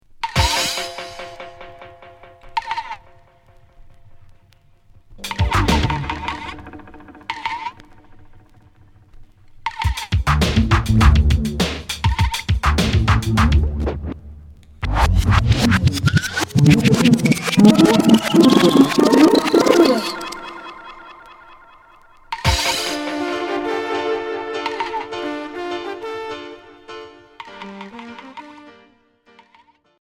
Reggae dub